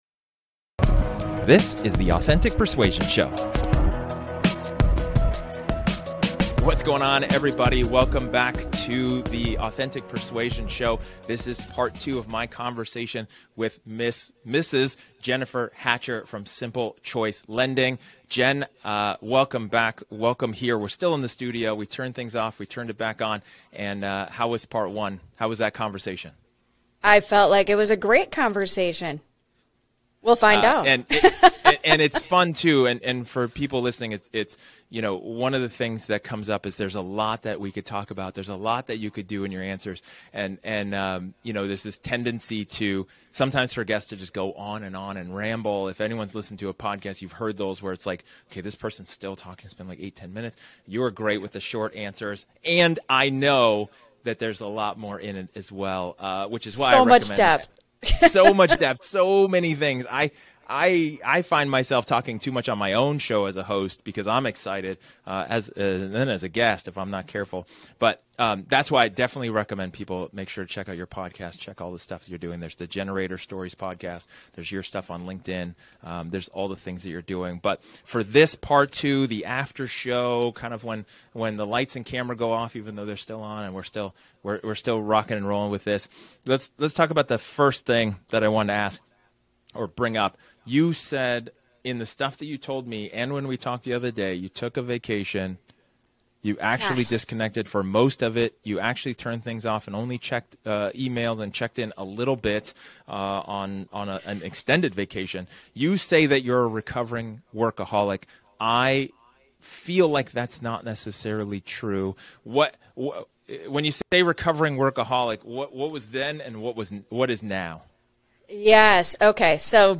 This is a casual conversation, off the cuff, and unscripted.